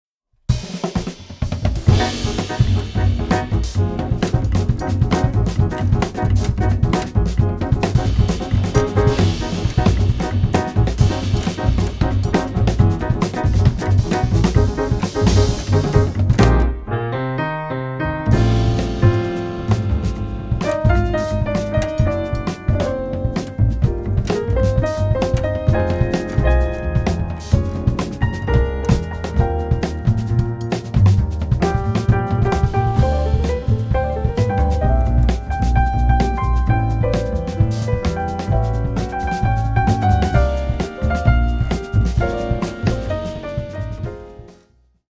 クールで情熱的なオリジナルアレンジがジャズの伝統と現代を融合。
深いグルーヴ、洗練された旋律、躍動感が織りなす極上のサウンド。